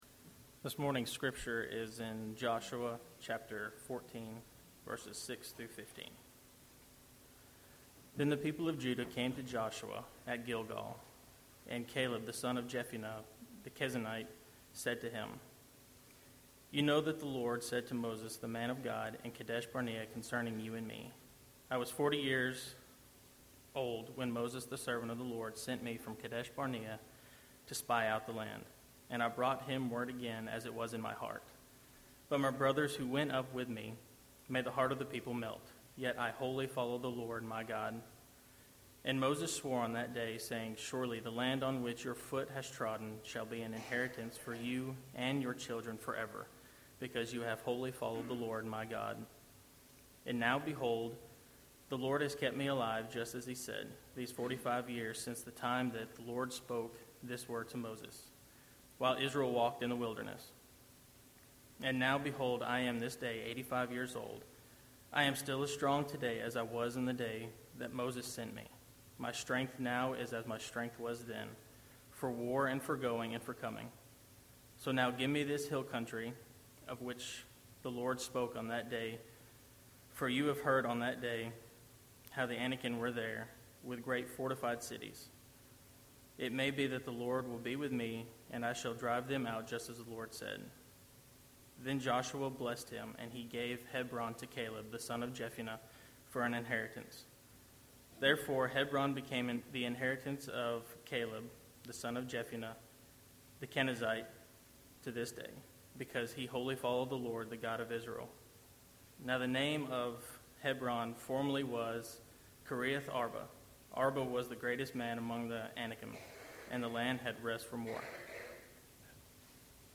A sermon in a series through the book of Joshua.